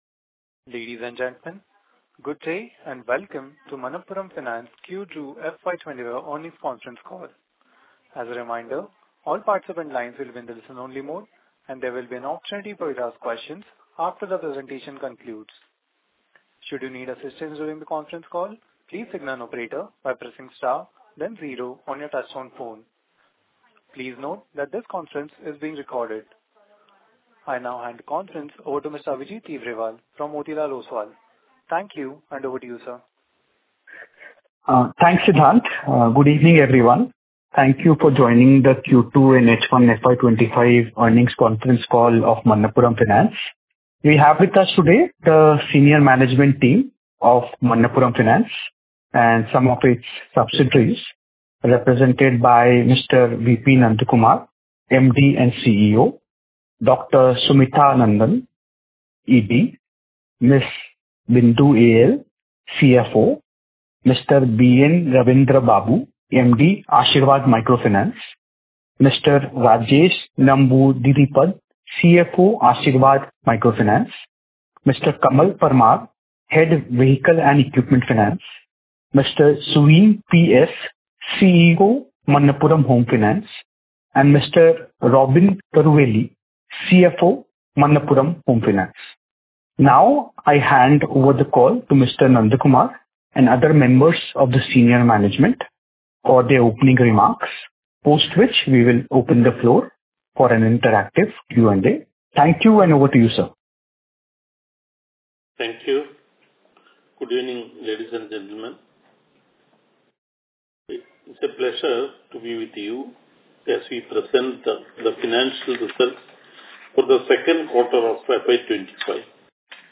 Investor Call | Manappuram Finance Limited